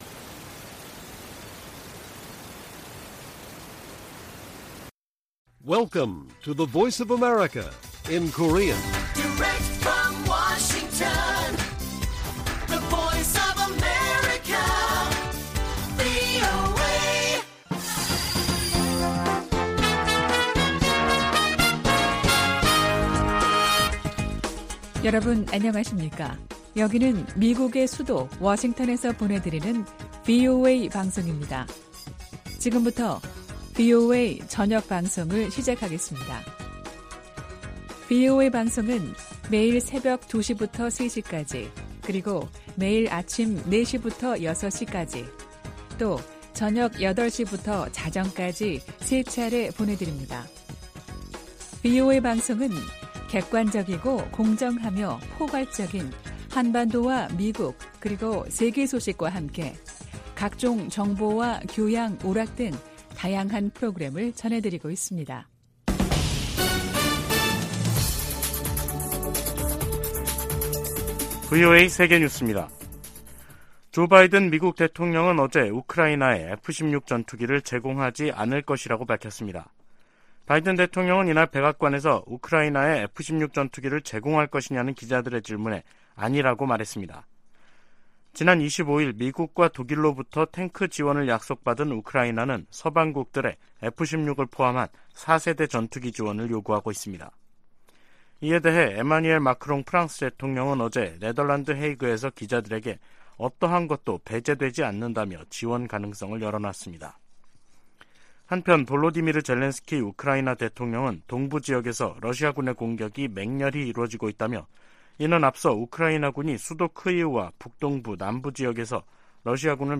VOA 한국어 간판 뉴스 프로그램 '뉴스 투데이', 2023년 1월 31일 1부 방송입니다. 미-한 두 나라 국방 장관들이 서울에서 회담을 갖고, 한국에 대한 미국의 확고한 방위공약을 보장하기 위해 미국의 확장억제 실행력 강화 조치들을 공동으로 재확인해 나가기로 했습니다. 북한이 함경남도 마군포 엔진시험장에서 고체연료 엔진 시험을 한 정황이 포착됐습니다.